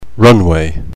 The learner can hear the word and the sentence pronounced in either a British or an American accent.